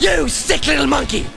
monkey.wav